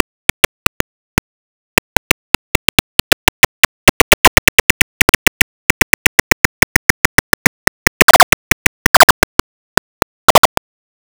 Bats typically emit 30 sounds per second countinuously while flying.
examples of bat echolocation
(frequencies lowered to human-audible frequencies)
Antrozous pallidus (Pallid Bat) 1